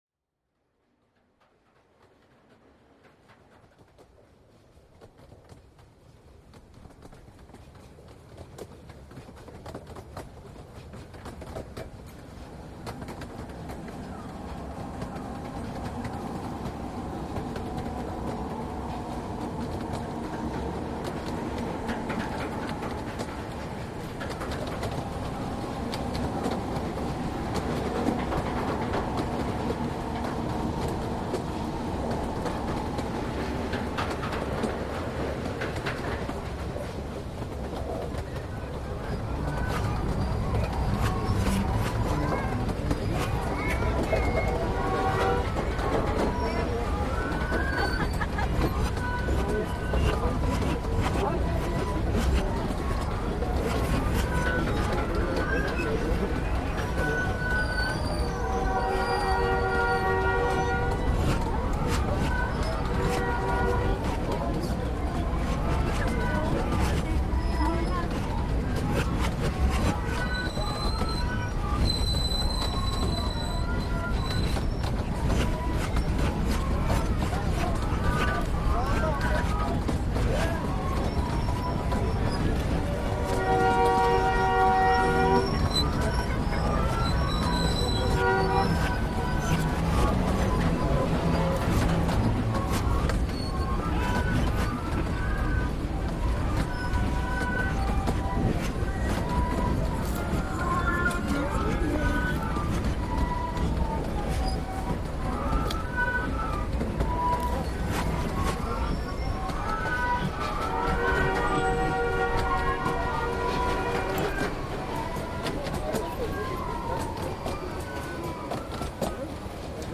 Peruvian regional train reimagined